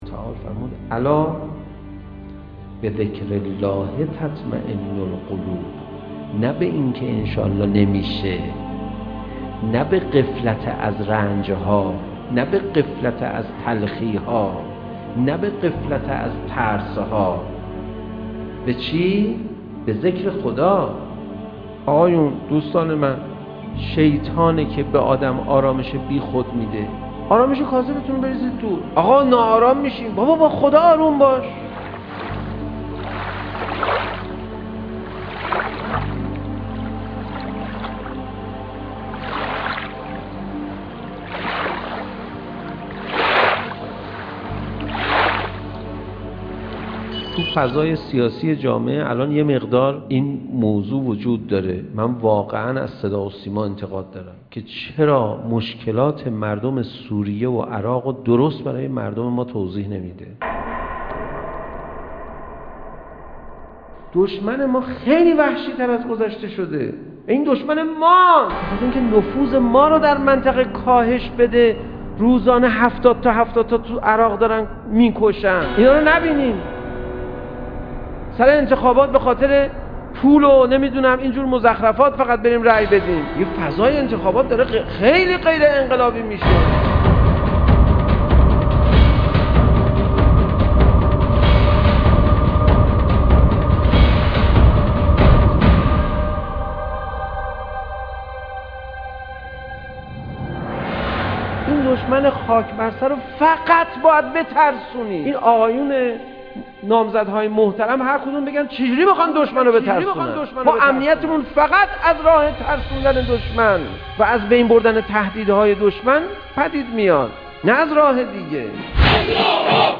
• دانلود سخنرانی استاد پناهیان, کلیپ صوتی, پناهیان